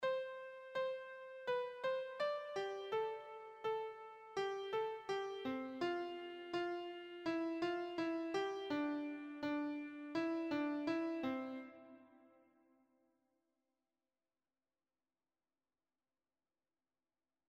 Patrón rítmico formado por dos negras, cuatro corcheas, dos negras y cuatro corcheas.